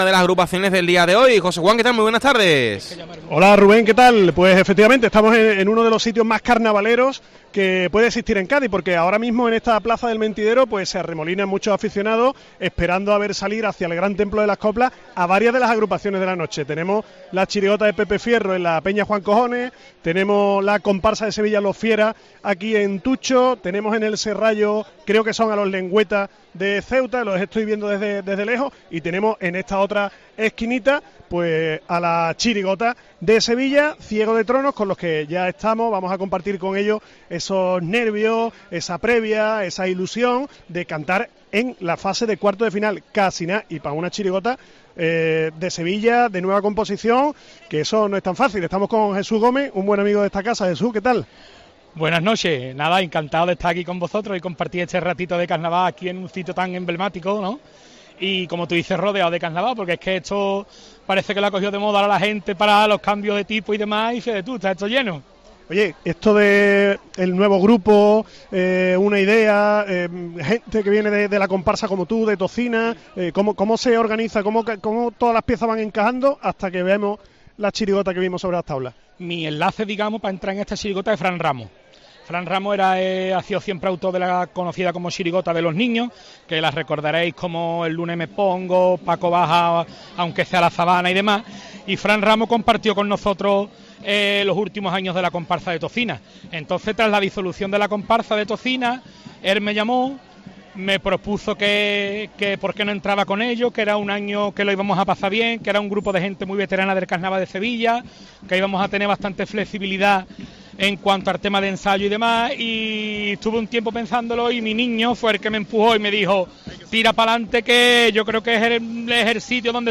La chirigota 'Ciego de Tronos' en COPE